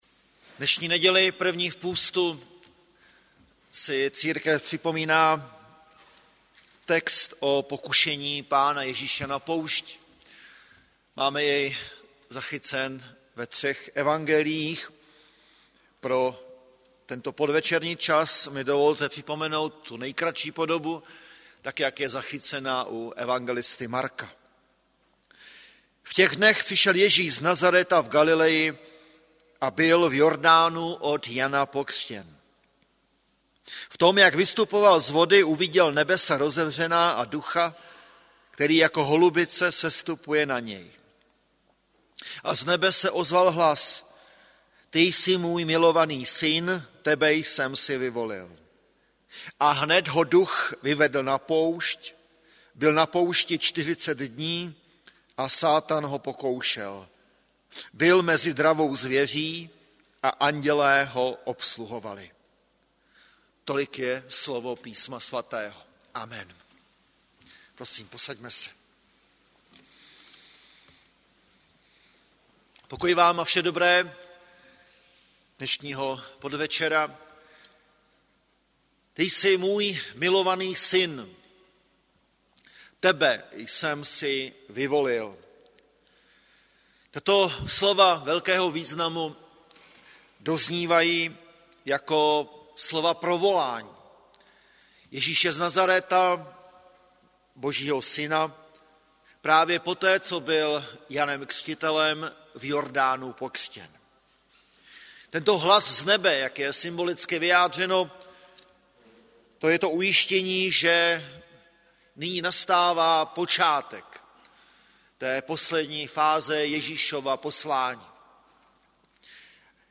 Kázání .